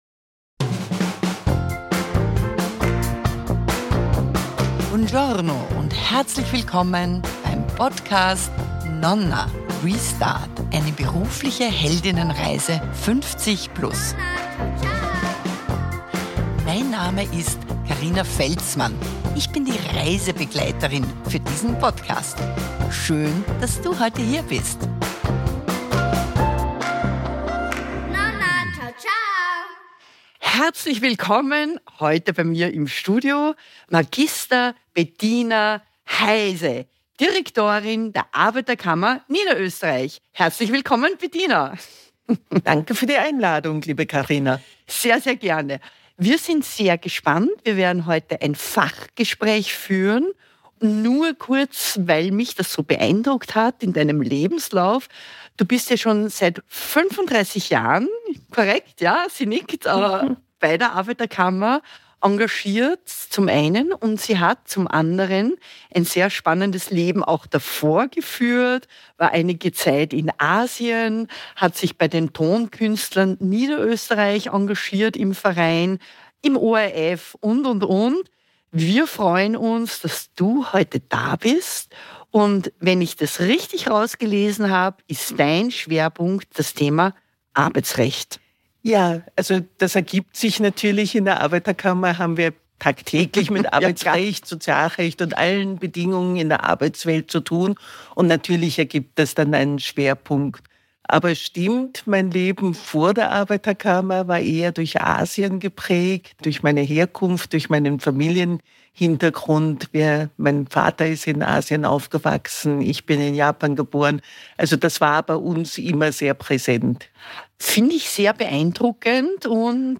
Fachinterview